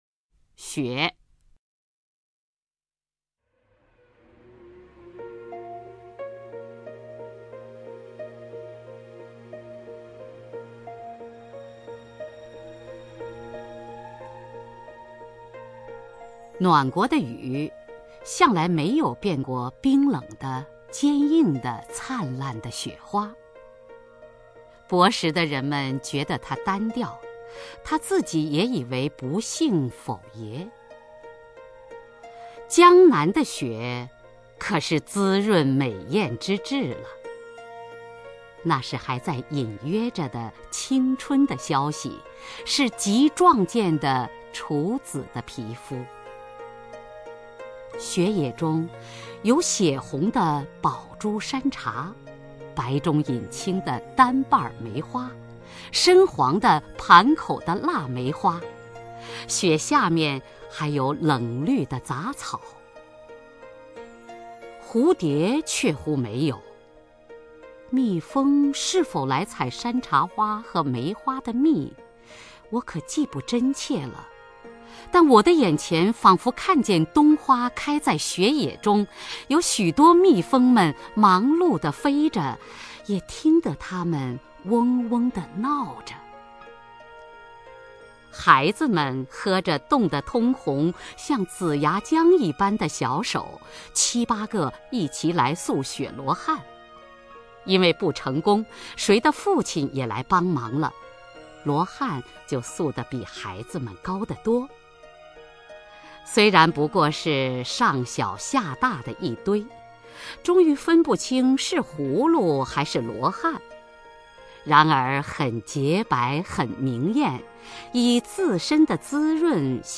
首页 视听 名家朗诵欣赏 雅坤
雅坤朗诵：《雪》(鲁迅)
Xue_LuXun(YaKun).mp3